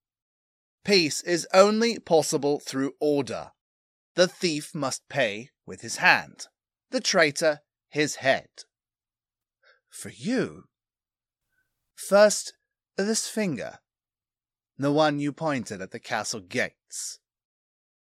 Voice Actor